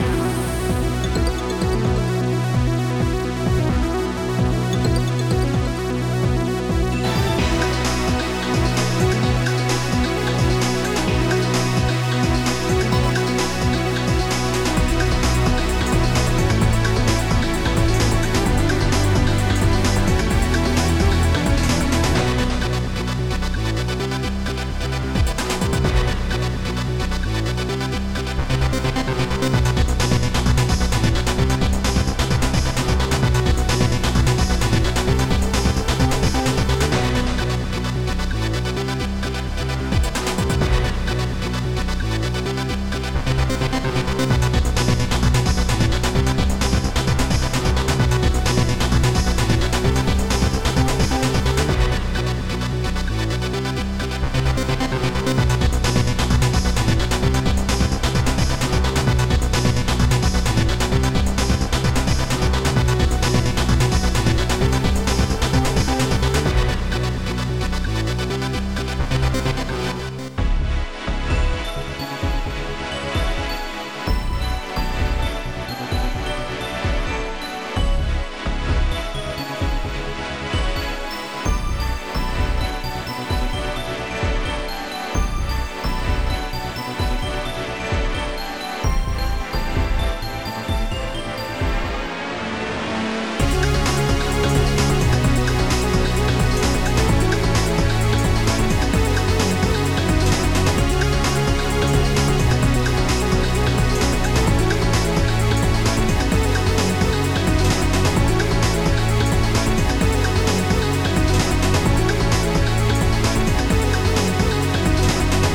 Thump Bassdrum
Snare e.r. Jazzy,slow
Saw.Synth.Lead
Gated Bass
WoodBlock2
Distorted Sweep
Xylophone.1
Ambient Power bells